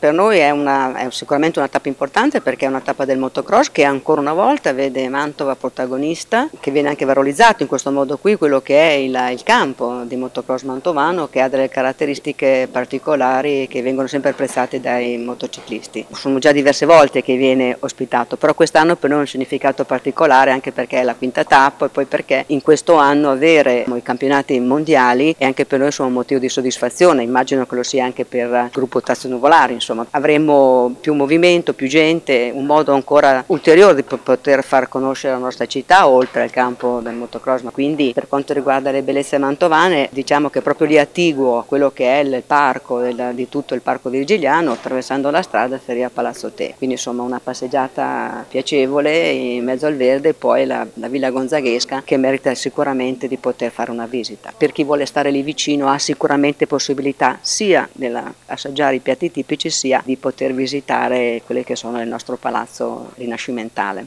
L’assessore allo Sport del comune di Mantova Paola Nobis:
Paola-Nobis-assessore-allo-sport-del-comune-di-Mantova.mp3